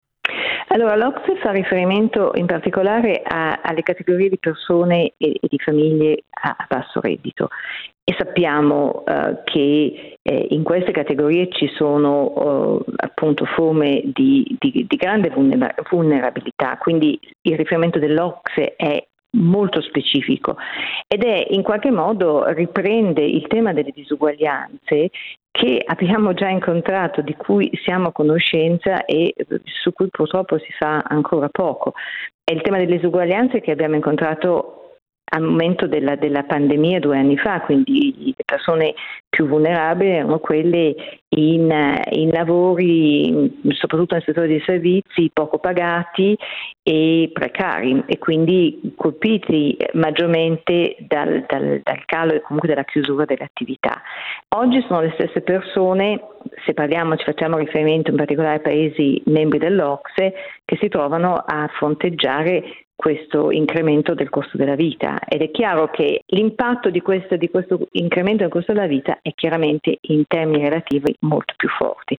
economista